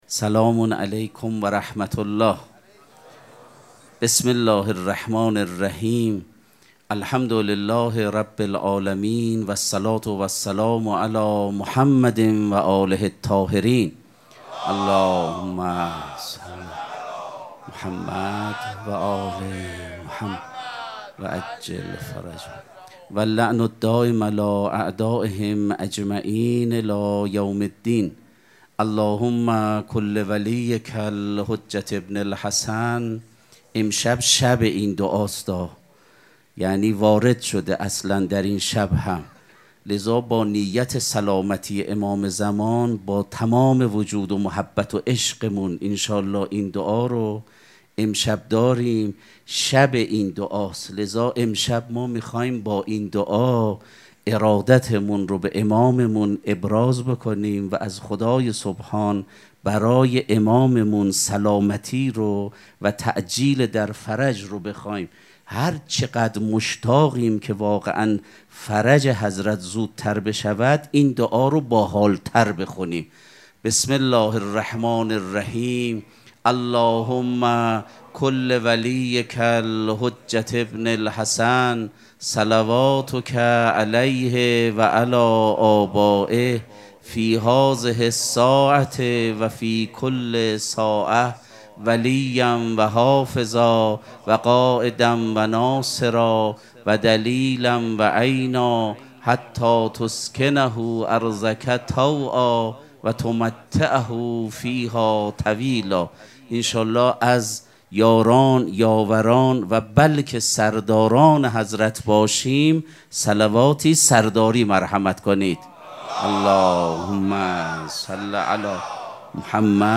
سخنرانی
مراسم مناجات شب بیست و سوم ماه مبارک رمضان یکشنبه ۳ فروردین ماه ۱۴۰۴ | ۲۲ رمضان ۱۴۴۶ حسینیه ریحانه الحسین سلام الله علیها